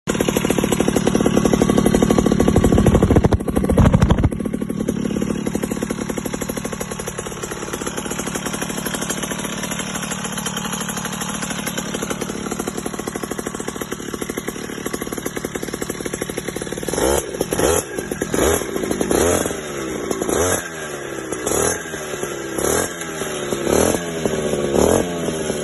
Sound simonini🤩💙/ Config: 70cc psr, sound effects free download